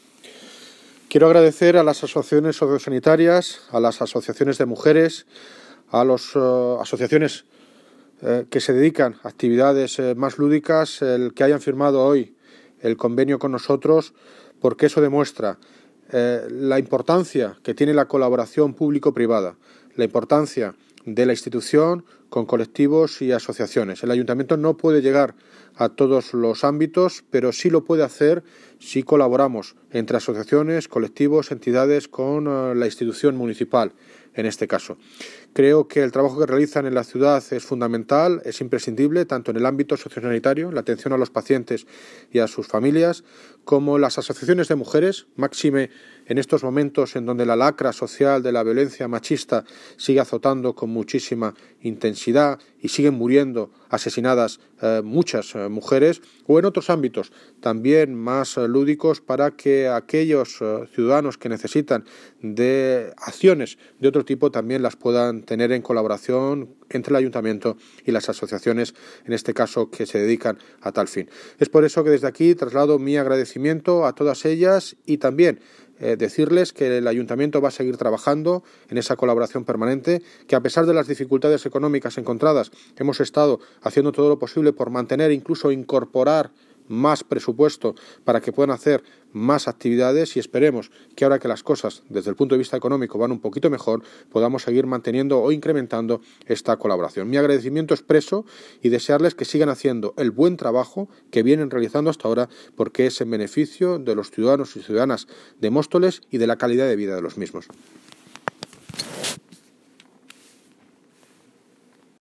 Audio - David Lucas (Alcalde de Móstoles) Sobre firma convenios con asociaciones